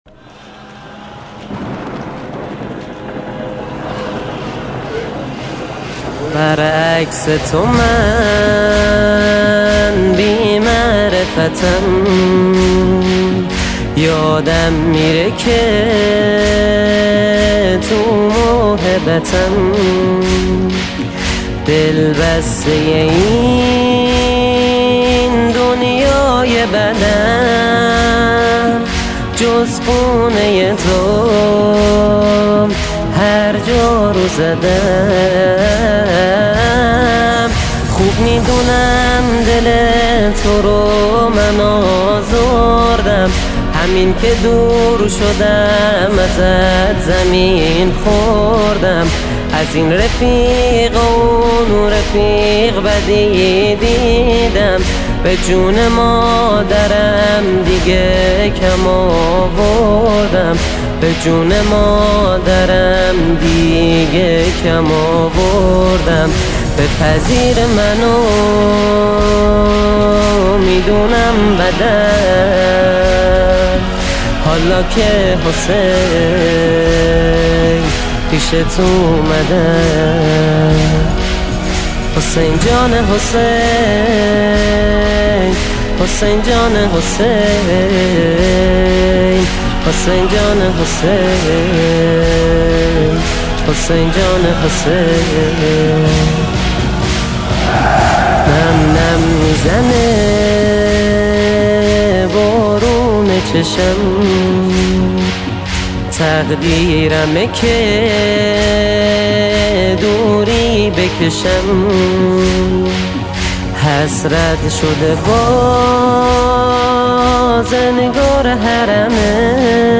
ویژه ایام ماه محرم 1400